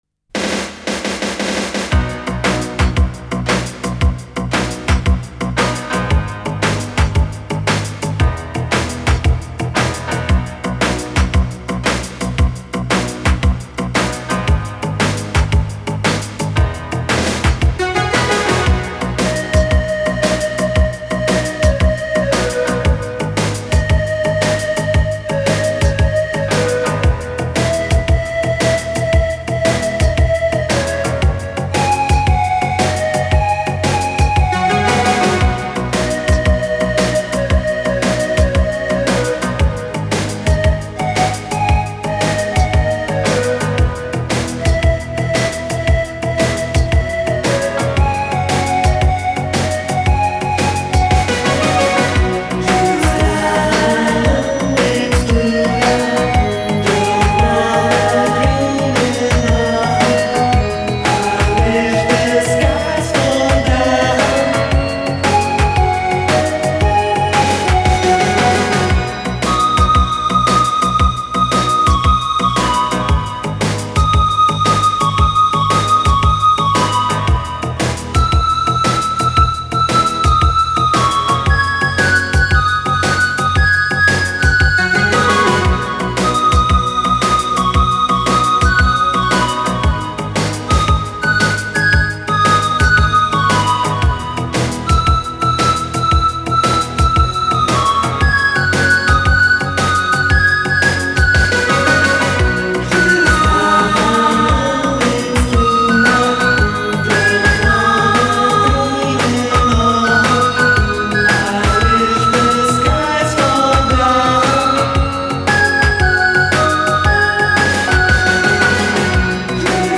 The Pan Flutes